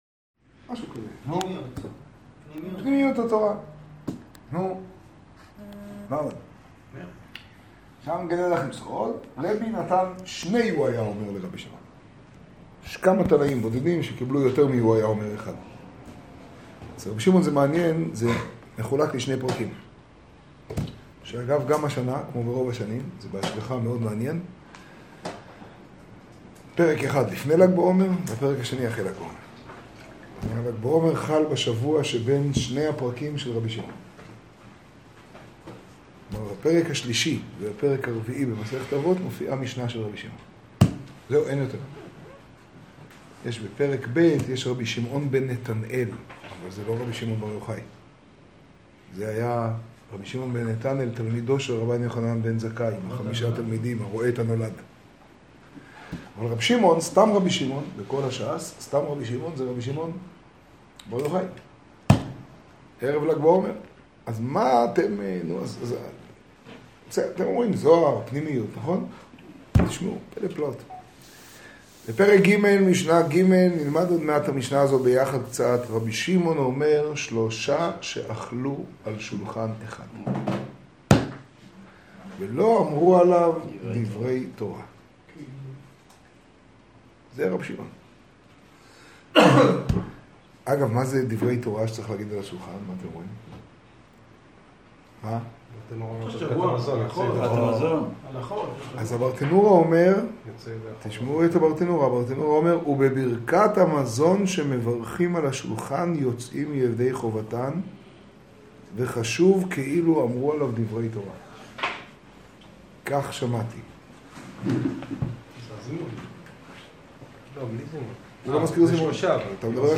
האזנה קטגוריה: שיעור , שיעור בפתח תקוה , תוכן תג: לג בעומר , תשעו → עשירי למנין.